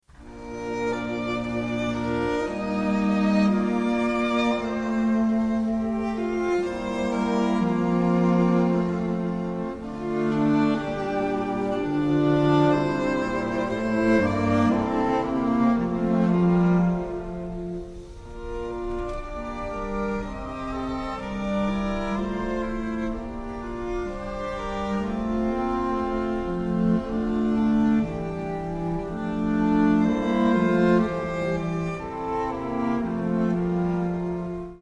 Strings 1